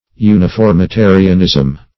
Search Result for " uniformitarianism" : The Collaborative International Dictionary of English v.0.48: Uniformitarianism \U`ni*form`i*ta"ri*an*ism\, n. (Geol.)
uniformitarianism.mp3